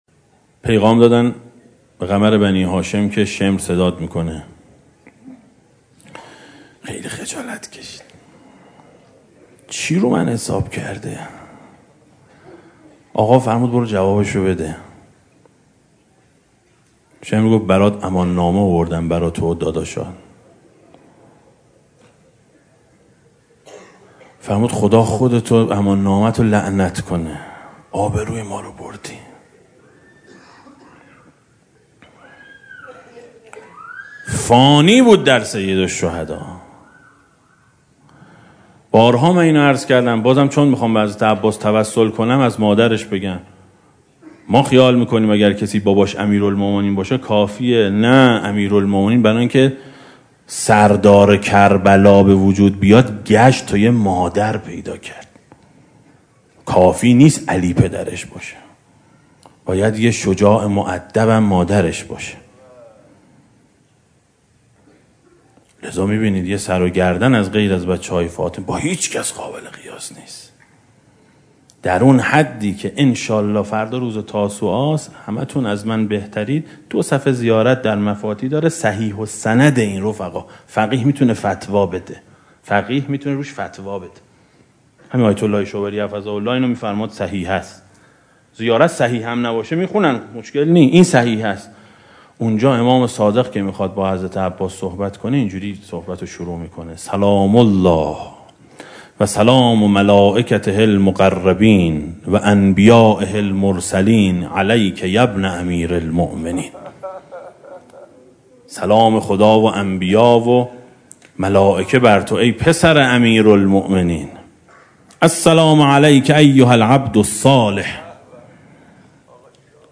روضه شب تاسوعای حسینی سال 1395 ـ مجلس دوم